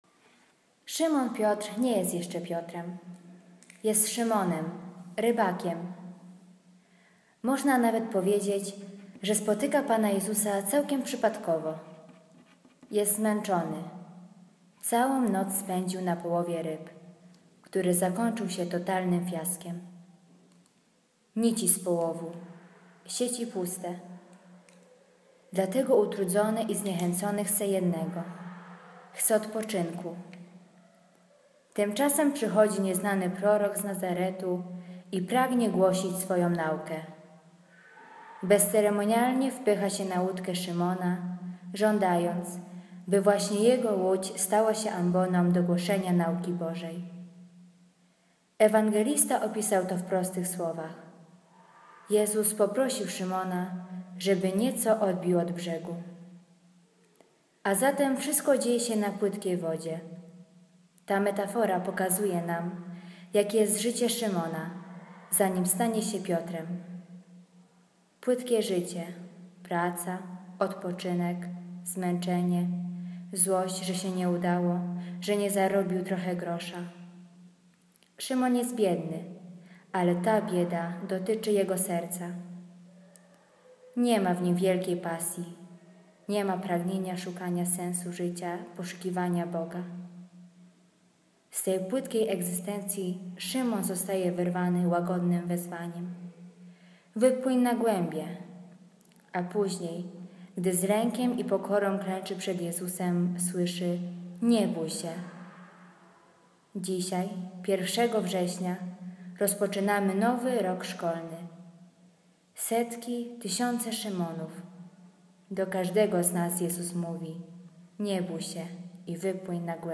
Komentarz do Ewangelii